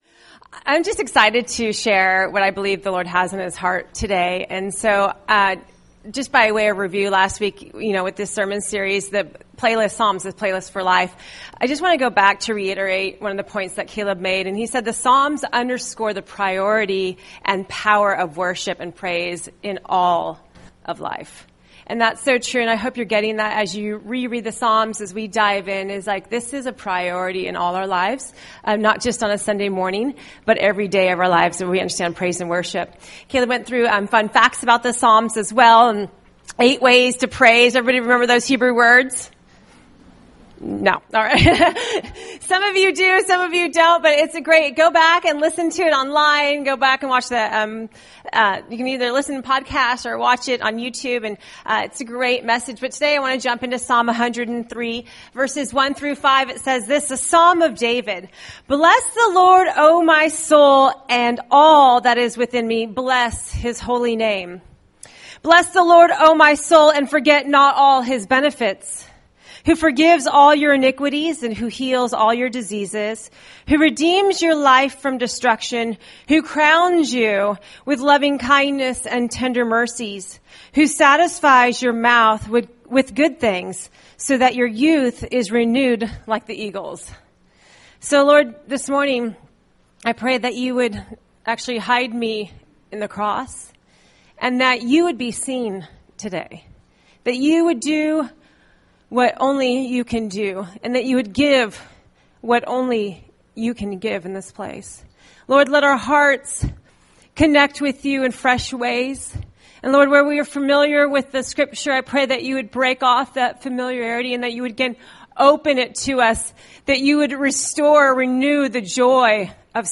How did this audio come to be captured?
Recorded at New Life Christian Center, Sunday, September 16, 2018 at 9 AM.